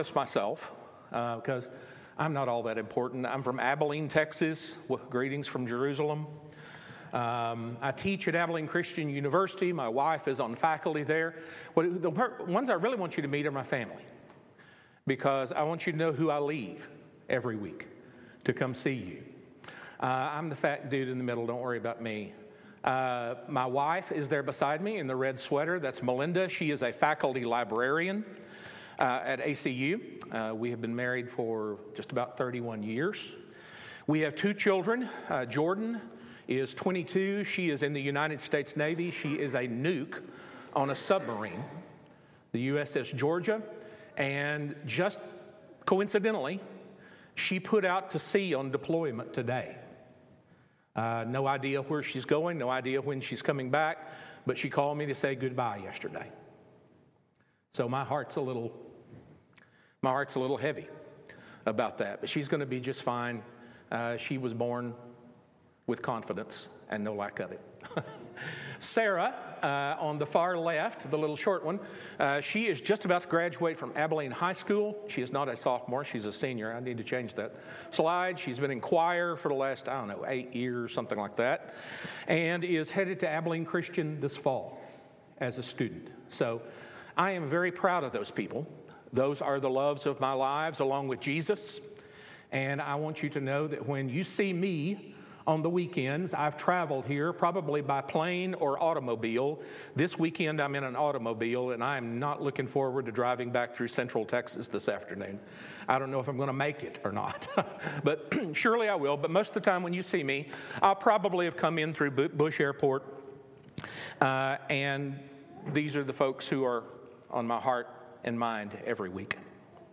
Weekly Sermon Audio “So What Do You Want?”